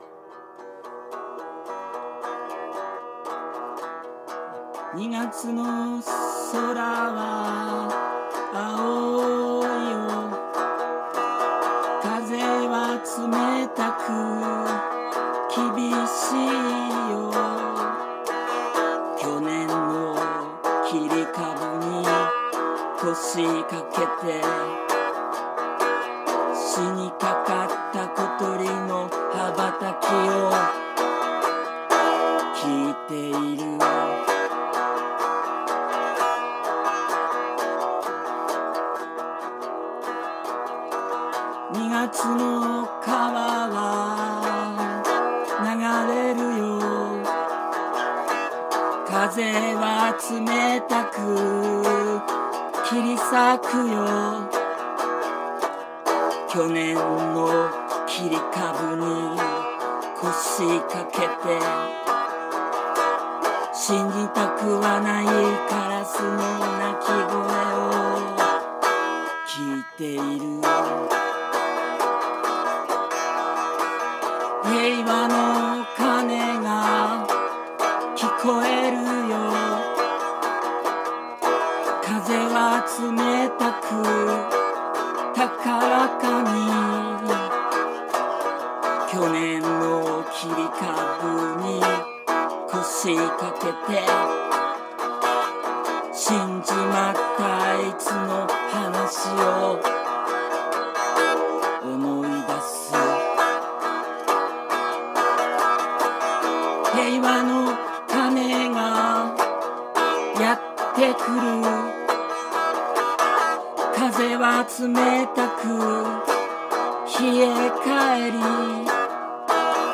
象さんギター1本で様々な詩（下記詩人参照！）にメロディをつけて歌うという好内容！